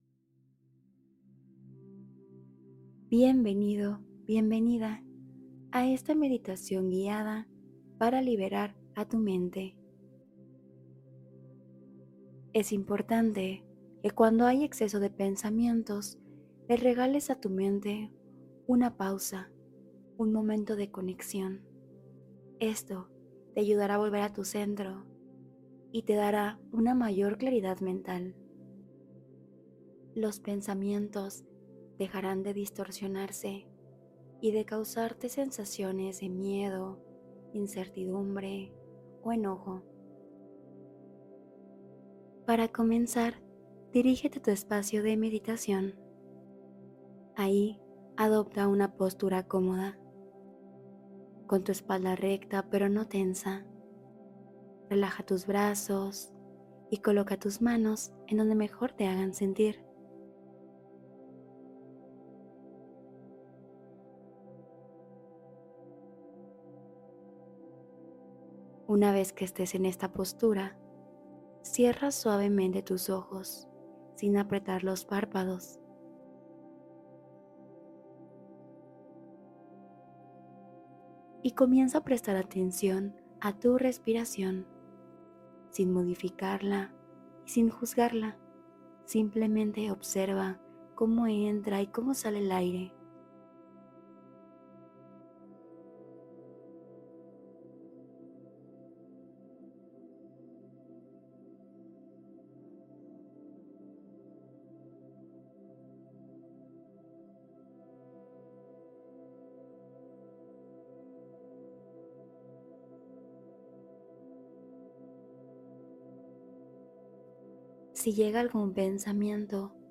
El Inicio de un Buen Día Nace Dentro de Ti: Meditación Matutina de Enfoque